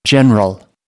17 general (n) /ˈdʒenrəl/ Vị tướng